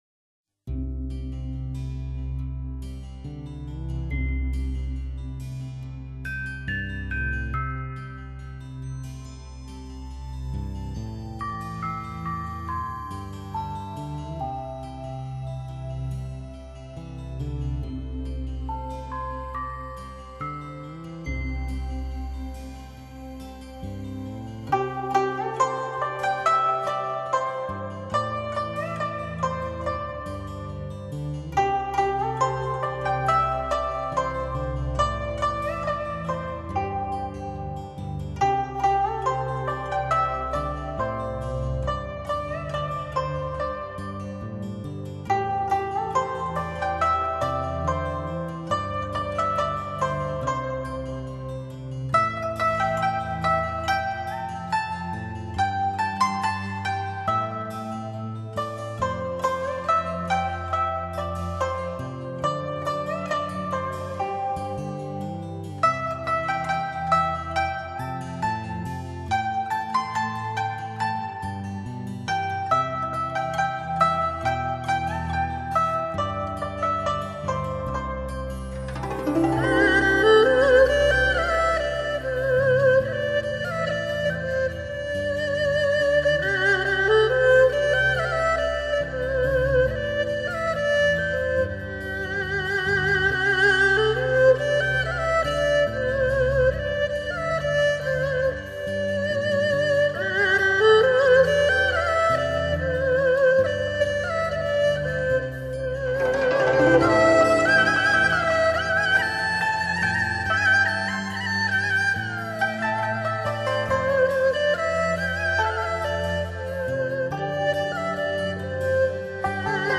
輕鬆、舒適、恬靜的音樂風格